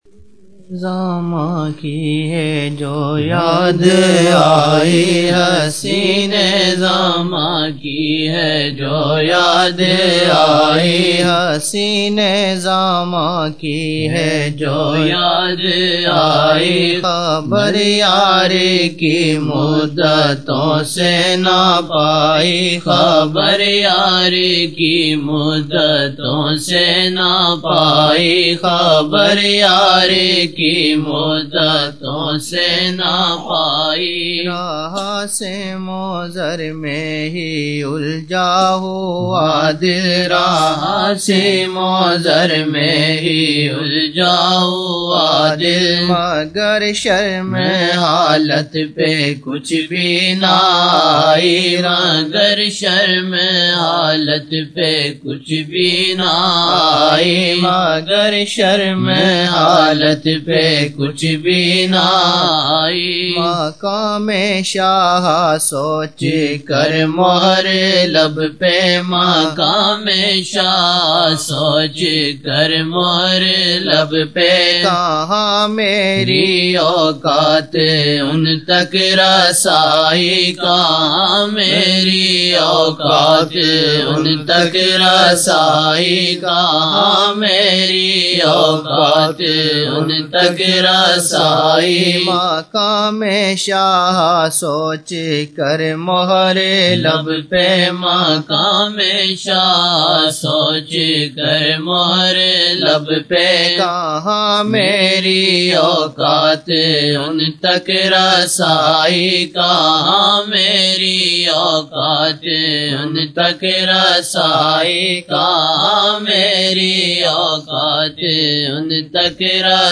Old Naat Shareef